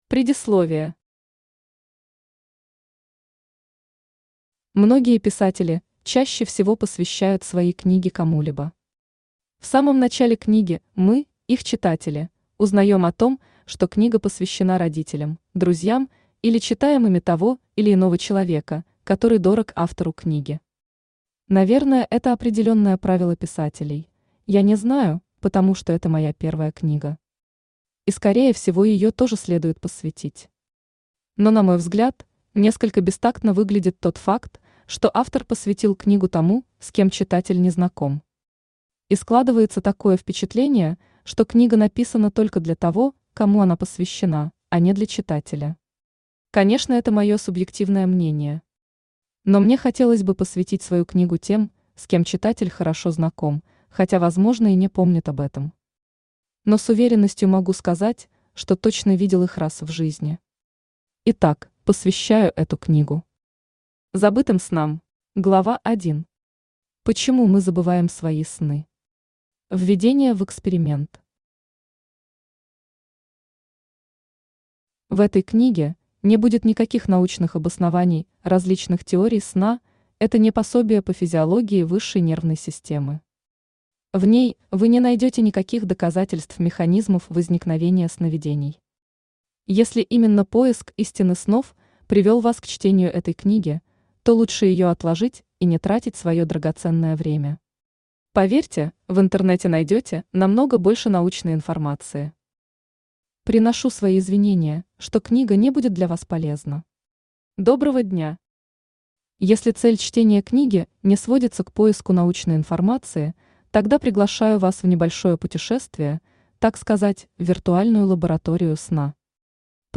Aудиокнига Забытые сны Автор RUSH Читает аудиокнигу Авточтец ЛитРес.